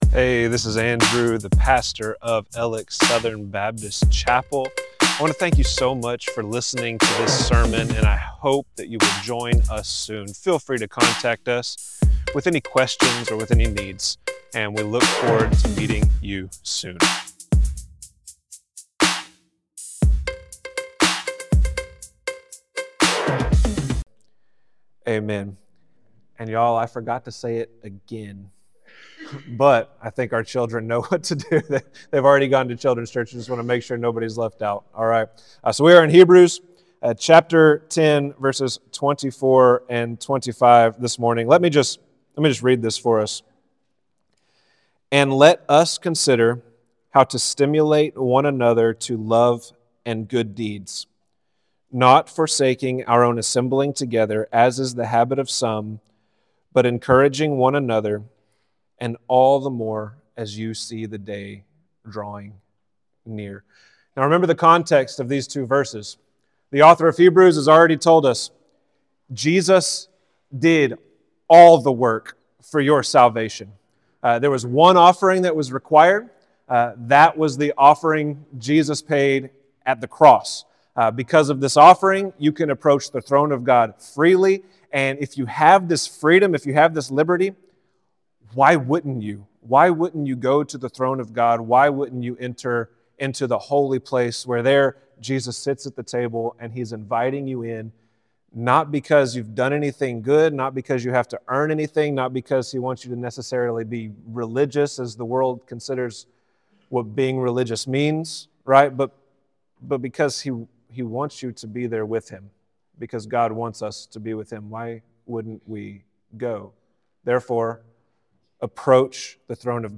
Alex Southern Baptist Chapel Sermons